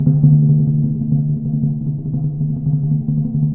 Index of /90_sSampleCDs/Roland LCDP03 Orchestral Perc/PRC_Ambo Timpani/PRC_Timp Roll 3